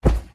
Minecraft Version Minecraft Version snapshot Latest Release | Latest Snapshot snapshot / assets / minecraft / sounds / mob / polarbear / step3.ogg Compare With Compare With Latest Release | Latest Snapshot
step3.ogg